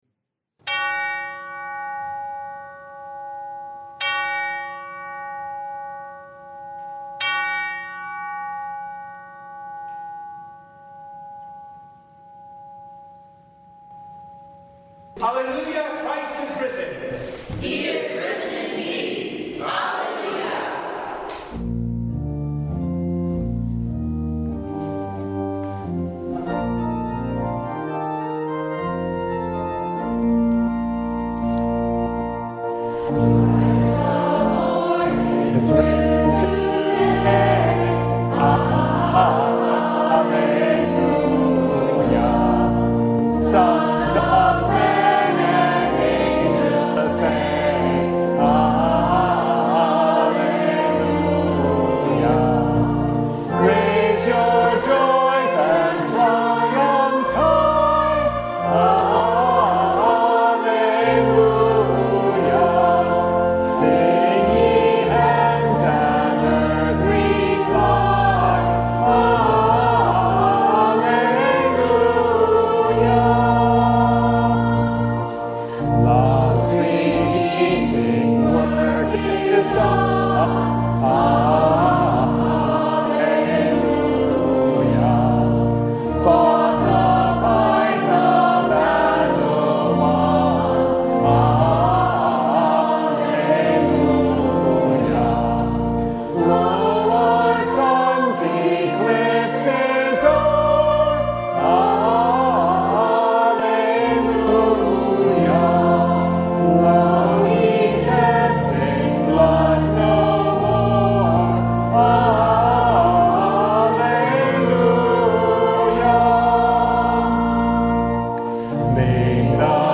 Matthew 28:1-10 Service Type: Easter Bible Text